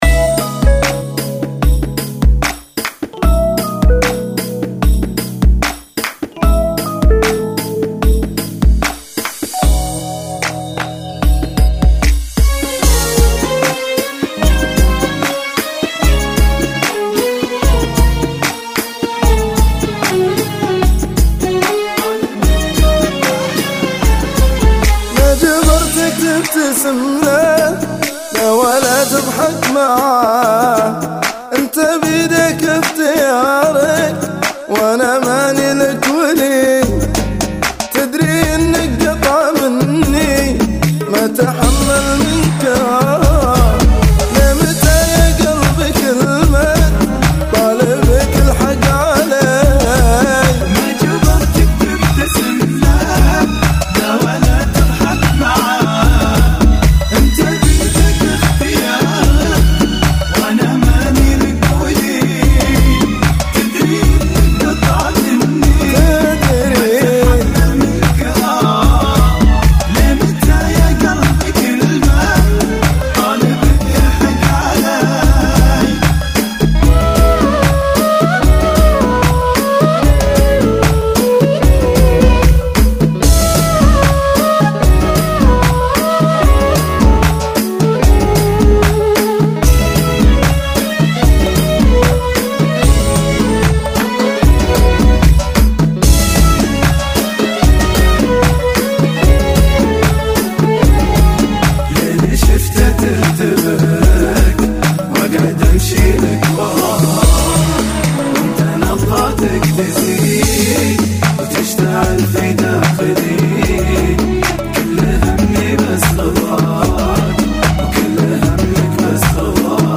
Funky [ 75 Bpm